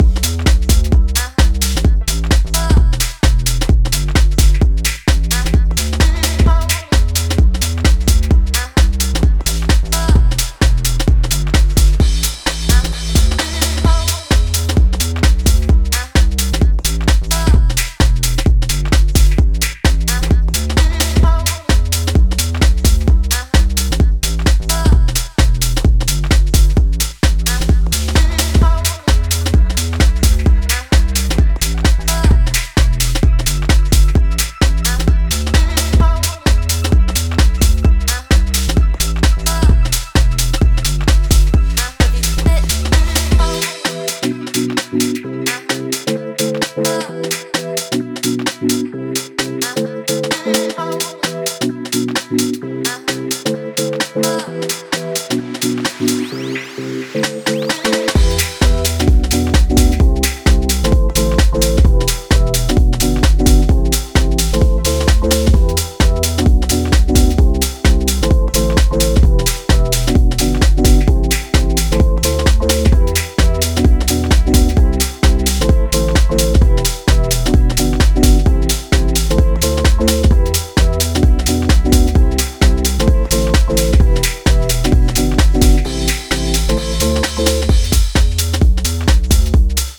BPM130までピッチアップしながら、モダン・クラシカルかつ疾走感溢れる内容へと仕上げています！
Instrumental Remix